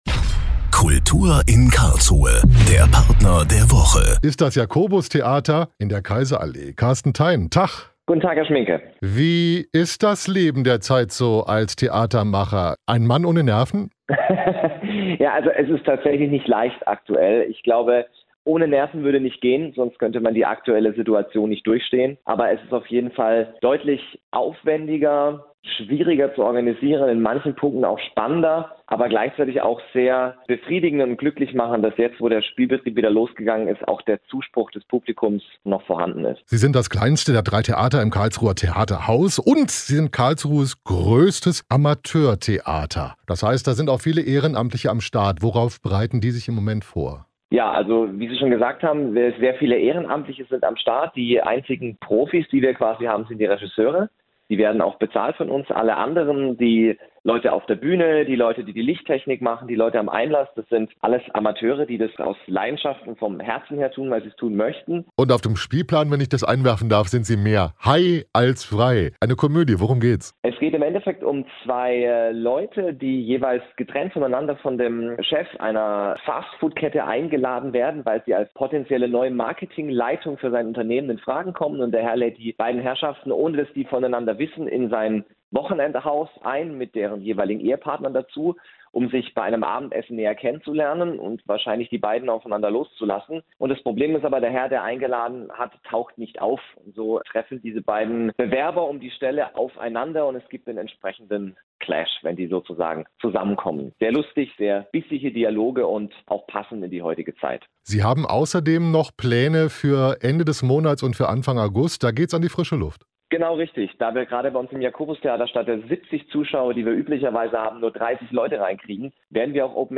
„Mehr high als frei“ – ein virtuelles Interview aus dem Jakobus-Theater